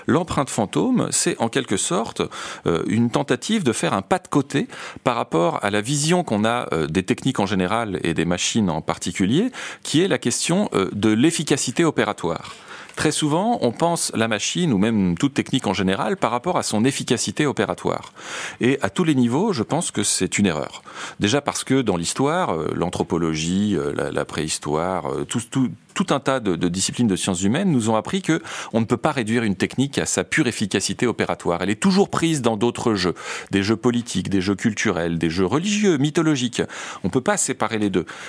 • Un cours-émission d'une heure en direct à la radio Graf'hit et diffusée en live sur PeerTube :
Des cours-émissions hebdomadaires d'une heure en direct sur la radio locale Graf'hit 94.9FM et sur Peertube